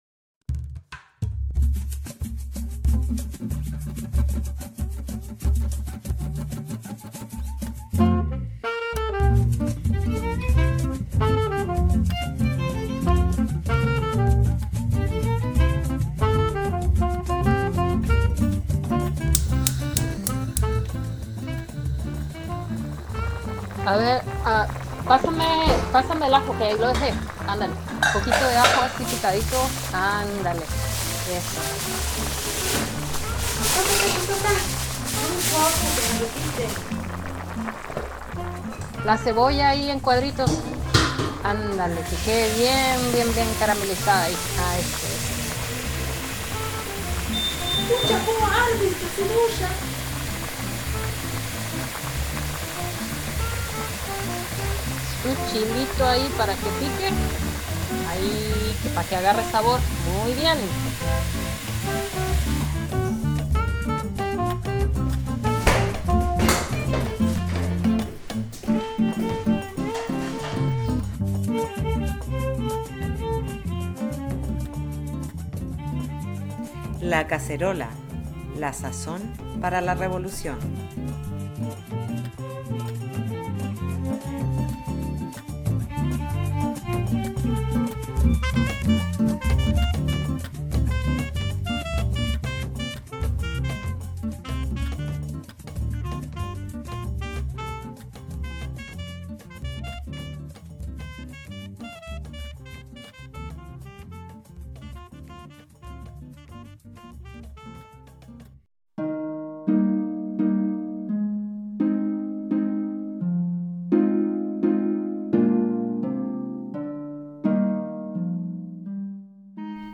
En este segundo programa producido por la Colectiva Comadre Luna, van a escuchar testimonios sobre la crianza en tiempos de COVID19, problemas a los que se enfrentaron otras comadres y sus estrategias. Conocerán a través de un reportaje La Otra Filadelfia esa que ocultan y tapan.